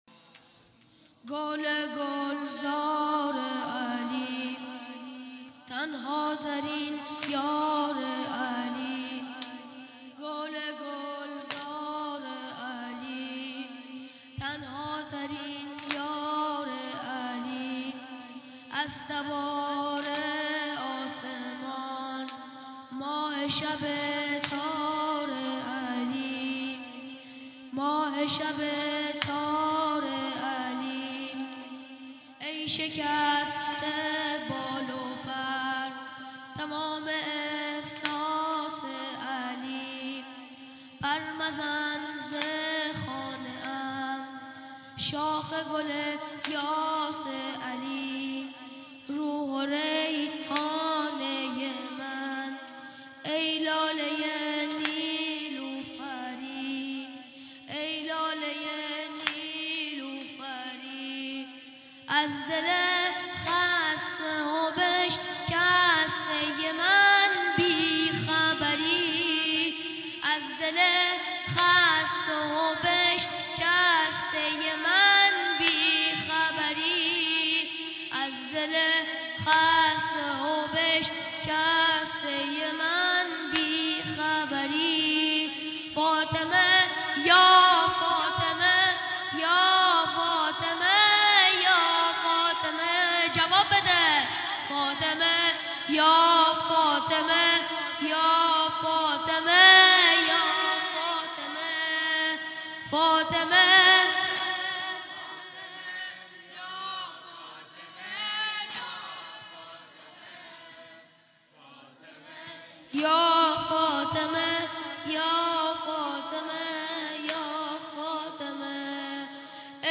جلسه هفتگی هیئت ذاکرین اهل البیت برازجان در حسینیه بقیه الله مصلی نماز جمعه در 28 دی ماه بزرگزار گردیدکه مداحی های زیر جهت دانلود قرار گرفته است.